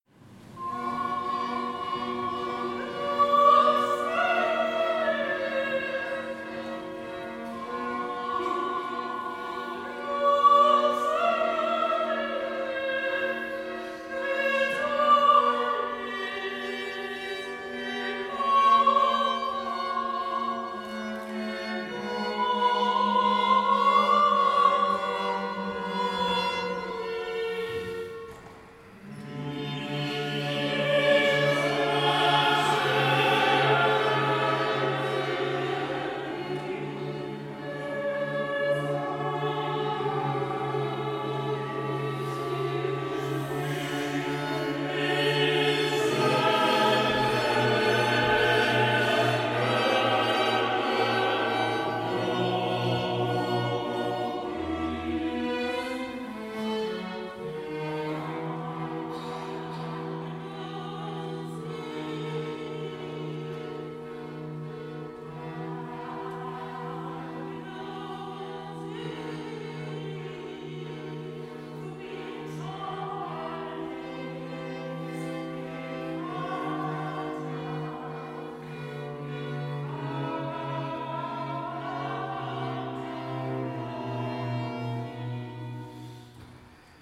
Ab 23:30 Uhr spielte eine Bläsergruppe der Stadtmusikkapelle Weihnachtslieder auf der Orgelempore der Pfarrkirche.
Ein Schola unterstützte den Gesang der Gemeinde.